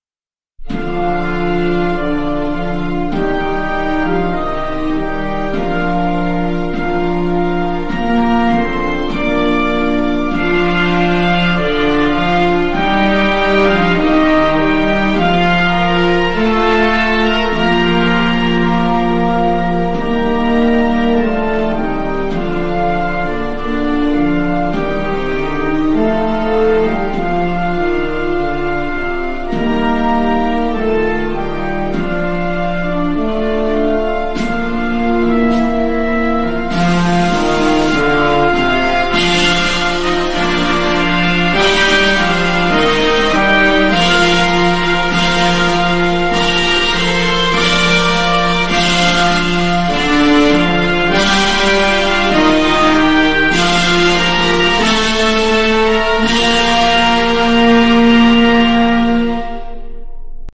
Sō-de_Taikai_(Instrumental).mp3